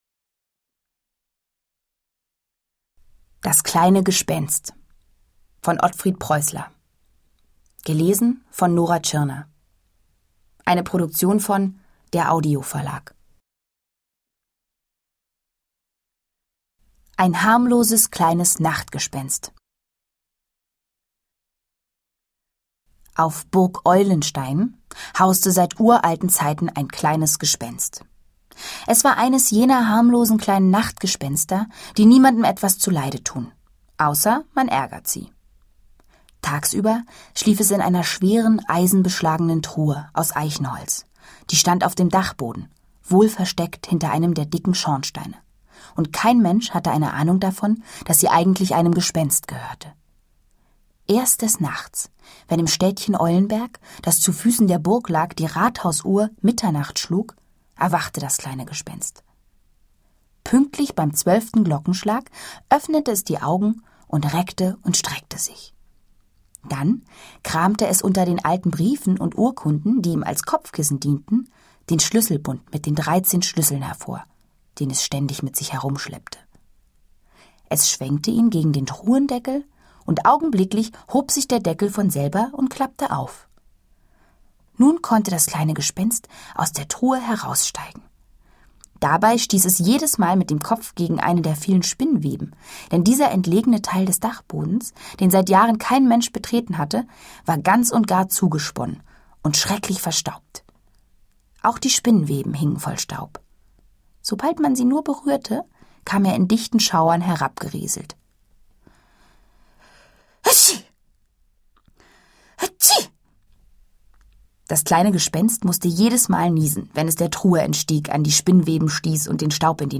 Ungekürzte Lesung mit Nora Tschirner (2 CDs)
Nora Tschirner (Sprecher)
Bezaubernd und mit viel Humor erzählt Publikumsliebling Nora Tschirner von den spannenden Abenteuern, die das kleine Gespenst auf der Burg Eulenstein erlebt.
Reihe/Serie DAV Lesung für Kinder